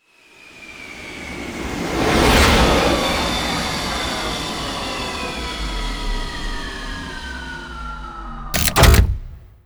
escape5.wav